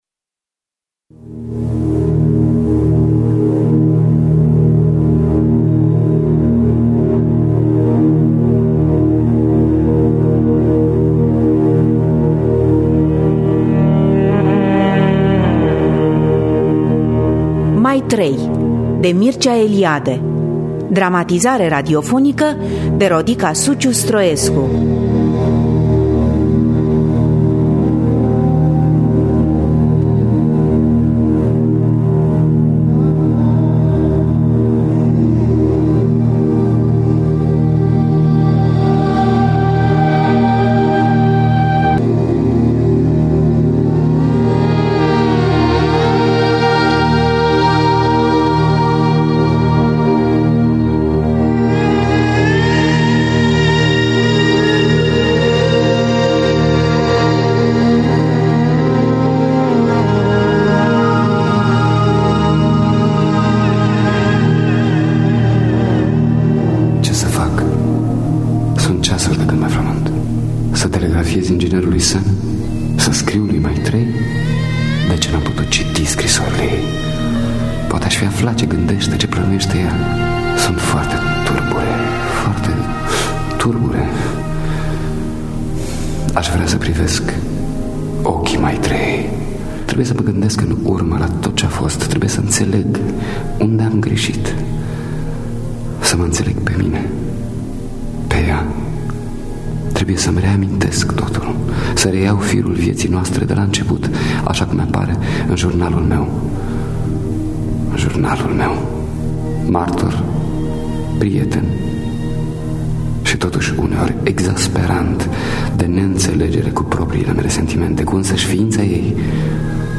Dramatizarea radiofonică de Rodica Suciu-Stroescu.